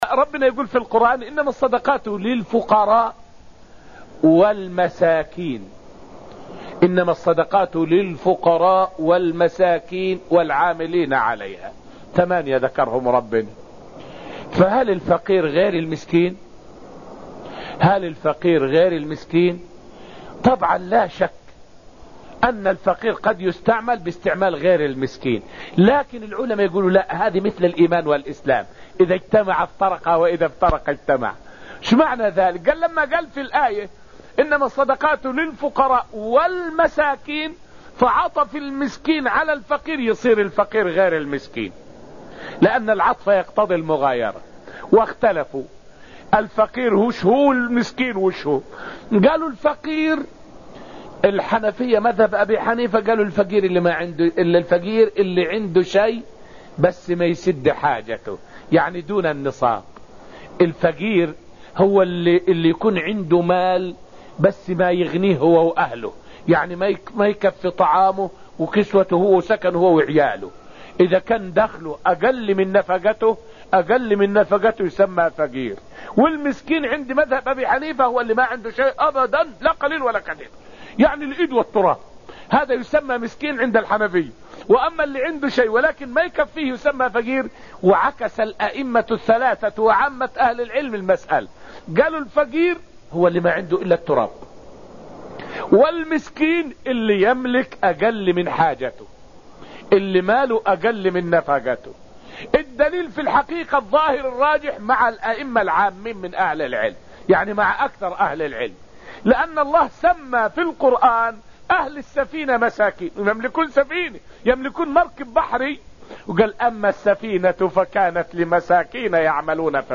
فائدة من الدرس الأول من دروس تفسير سورة الذاريات والتي ألقيت في المسجد النبوي الشريف حول النكتة البلاغية في الفرق بين سلام الملائكة وسلام إبراهيم.